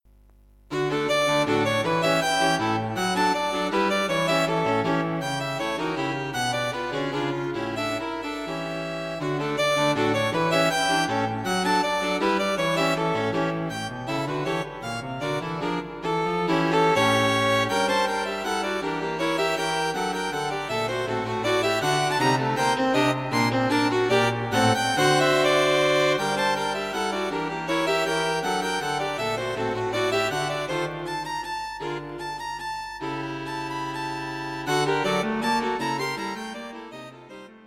String Quartet
The main themes have Twenty-One beats in them.
String Quartet.